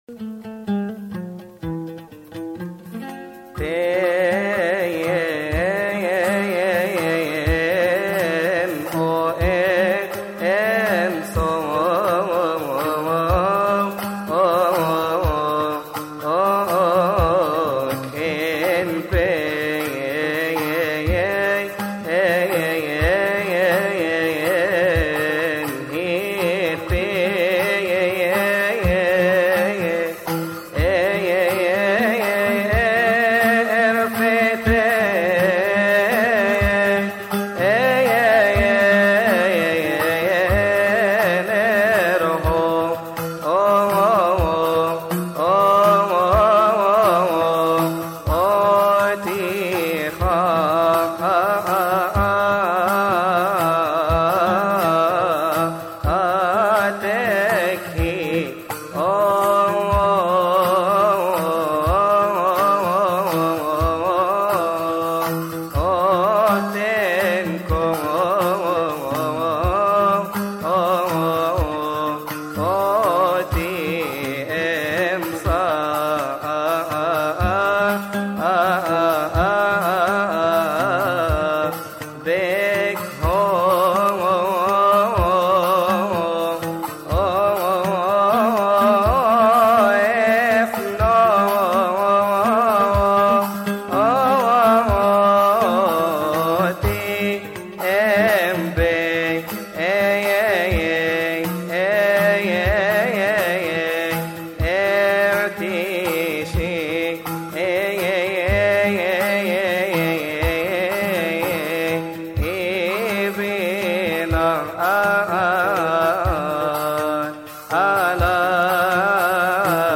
استماع وتحميل لحن لحن تين اويه أنسوك من مناسبة keahk